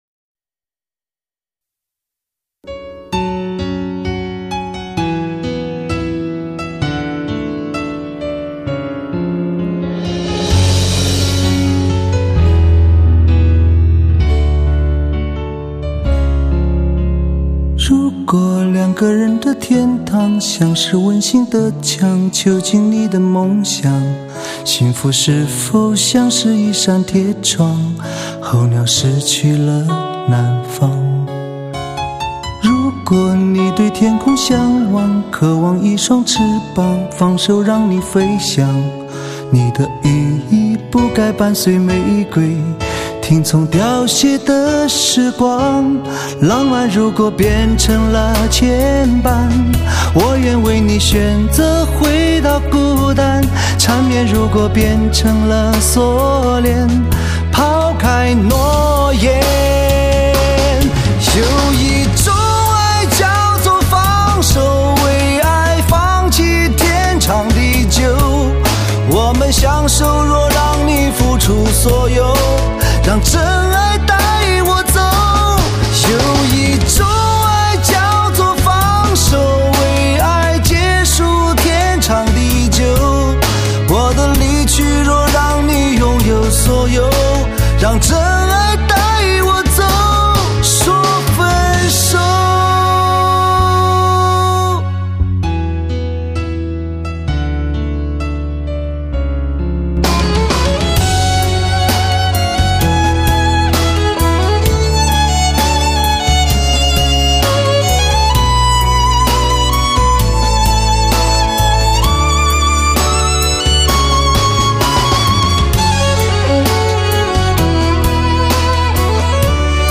唱片类型：汽车音乐